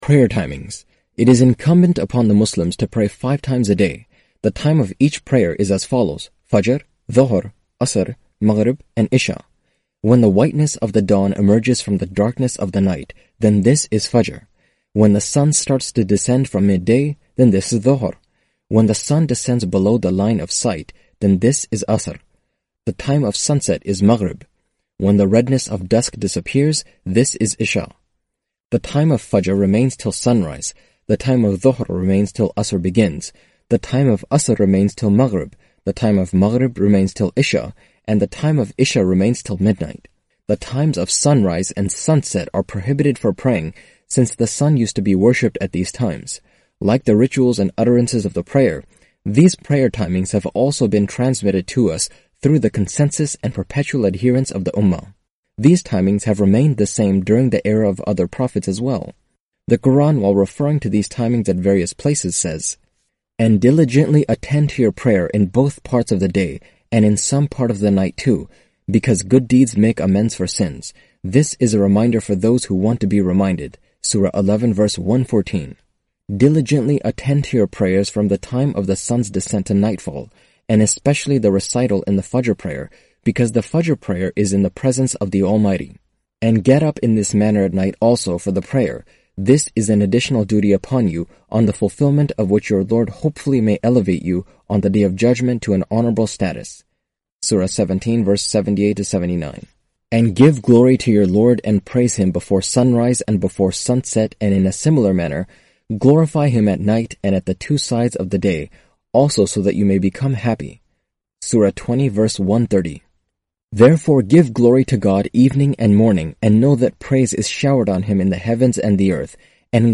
Audio book of English translation of Javed Ahmad Ghamidi's book "Mizan".